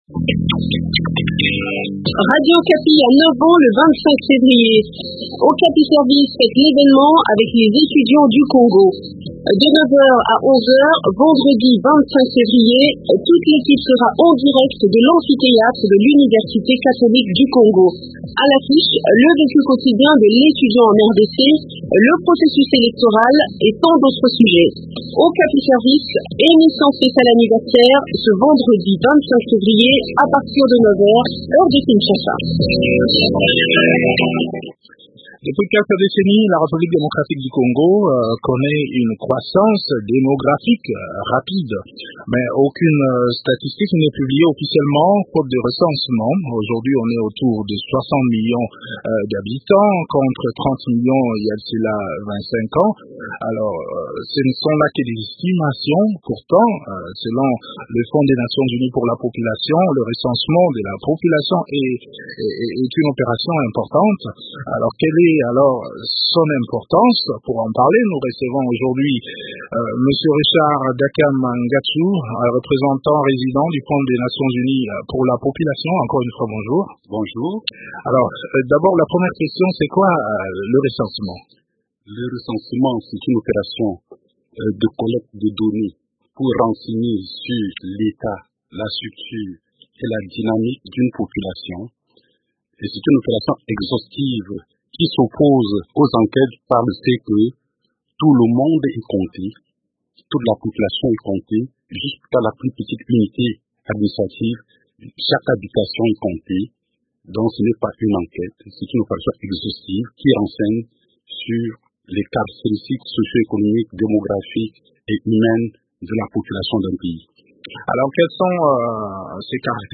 Eléments de réponse dans cet entretien